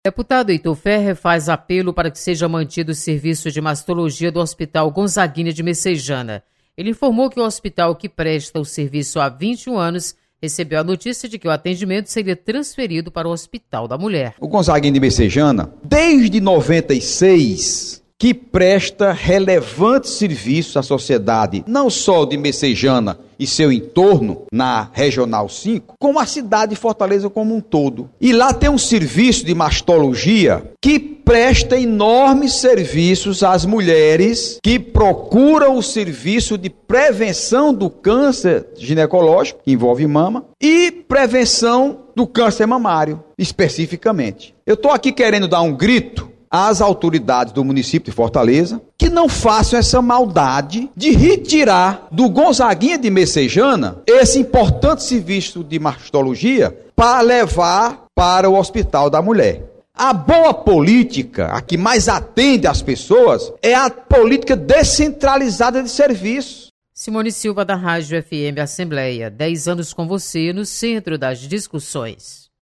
Plenário
Deputado Heitor Férrer defende manutenção de serviço de mastologia no Gonzaguinha de Messejana.